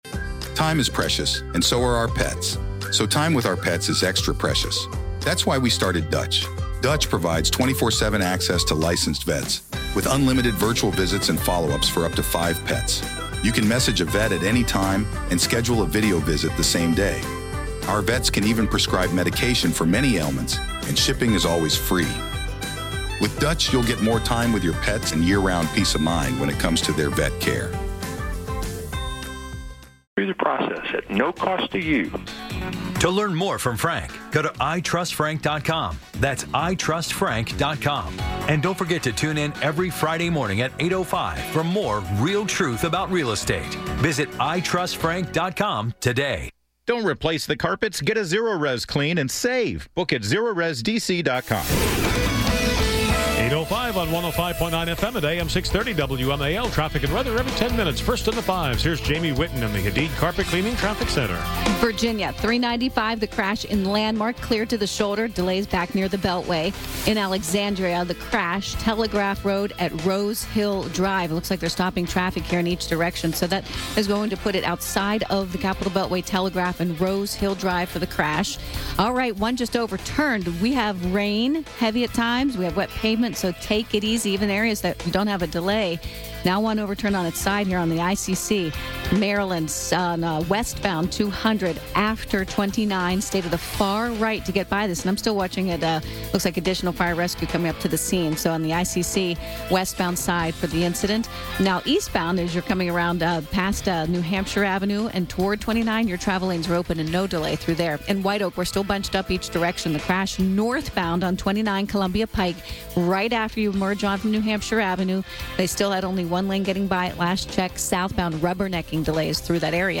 broadcast live from CPAC